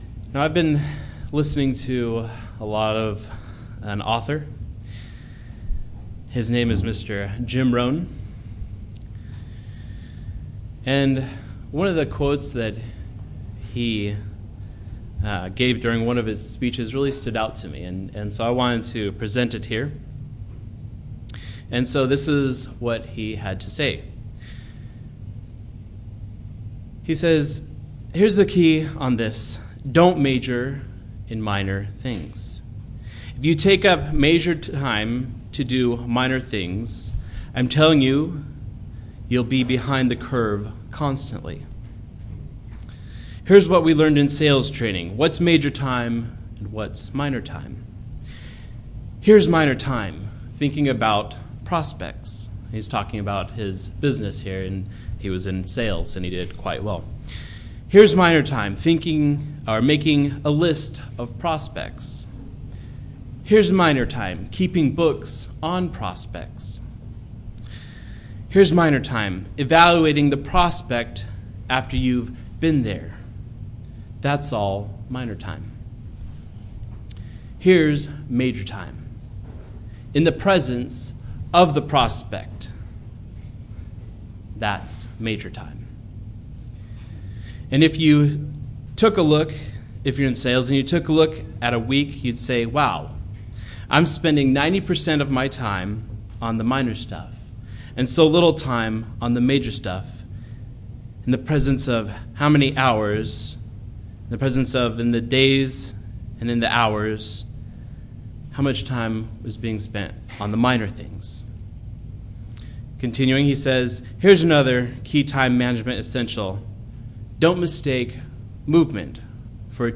Sermons – Page 92 – Church of the Eternal God